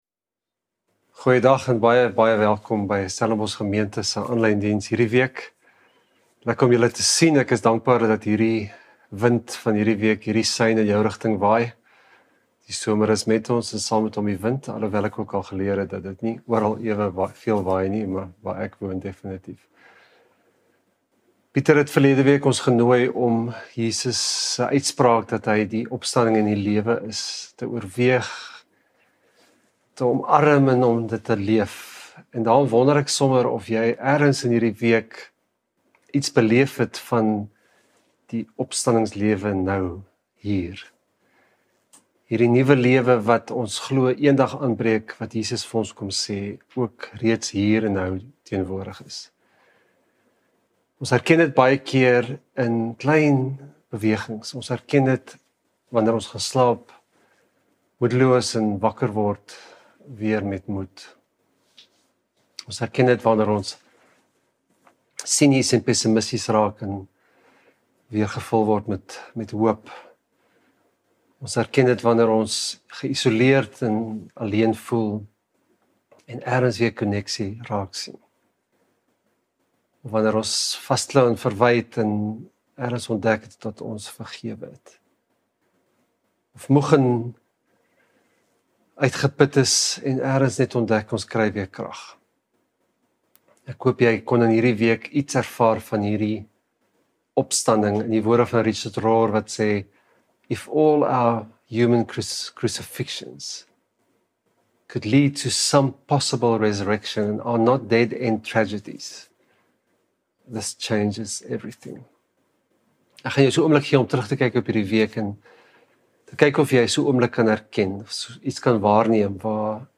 Stellenbosch Gemeente Preke 10 November 2024 || God Is...